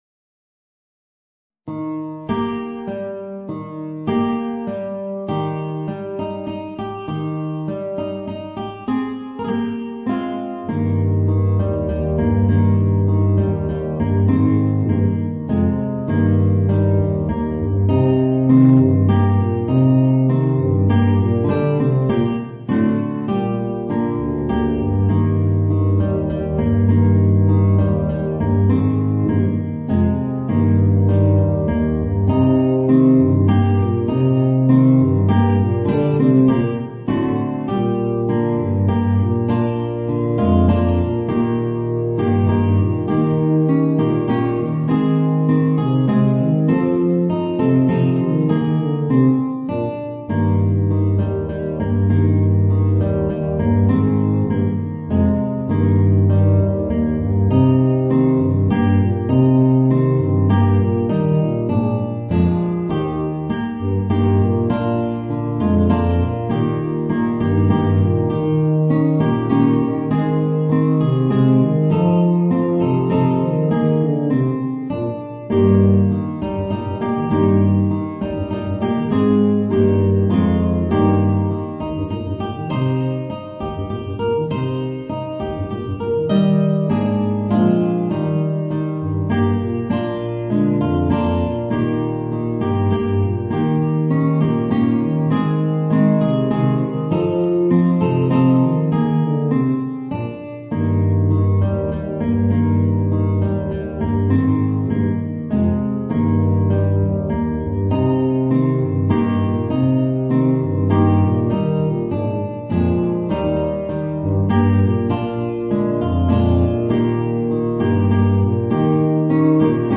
Voicing: Tuba and Guitar